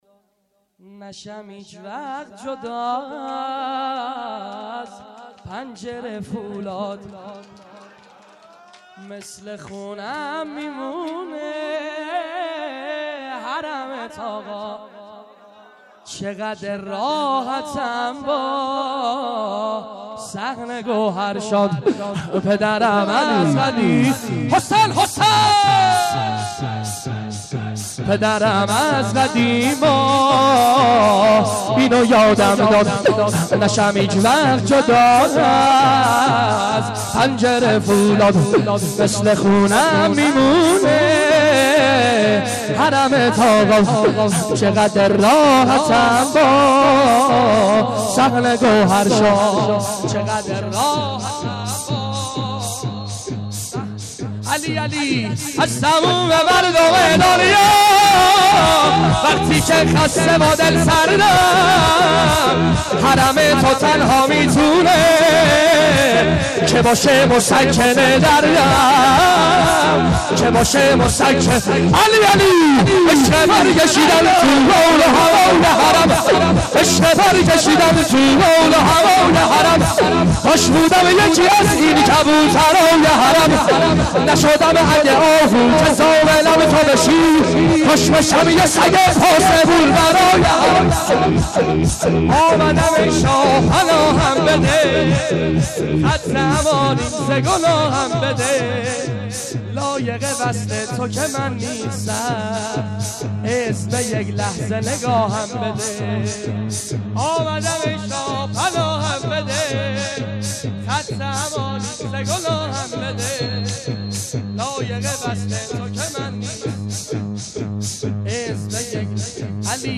اجتماع لبیک یا زینب هفتگی